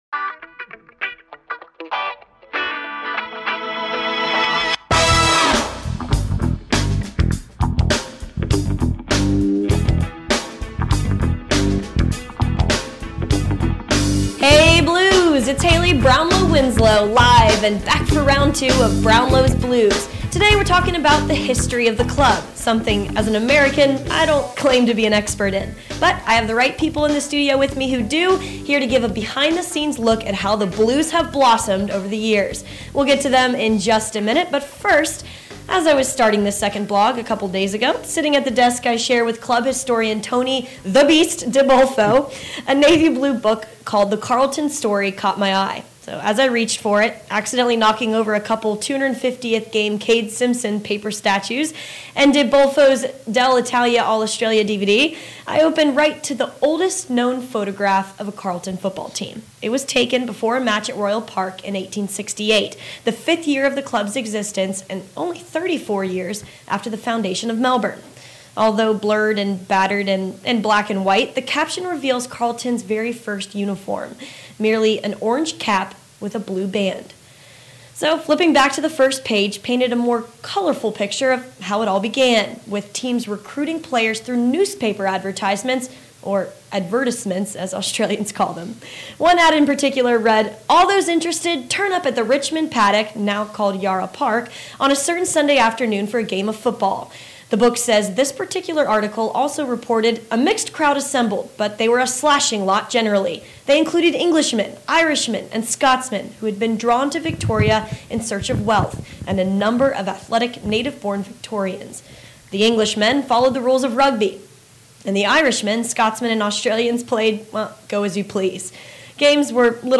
chats to a couple of the key figures in the Club's history